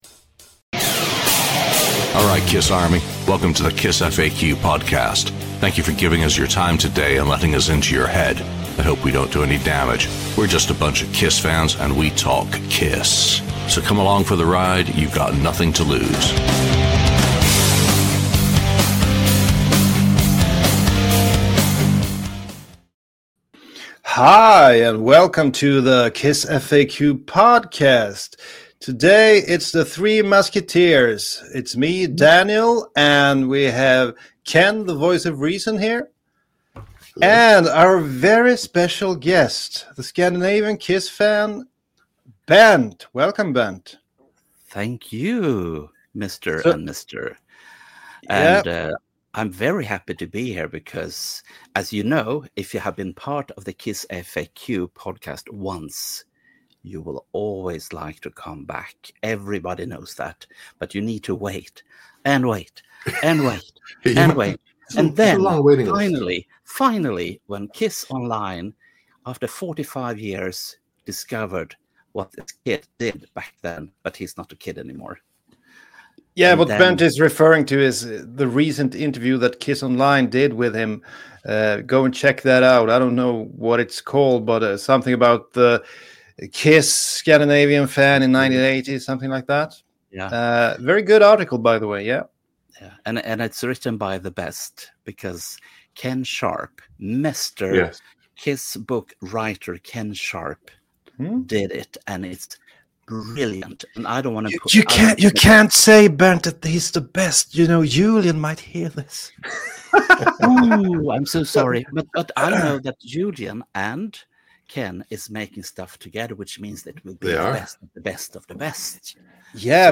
The KissFAQ Podcast is a weekly chat show featuring members of the KissFAQ message board and other guests discussing a wide variety of KISS-related topics.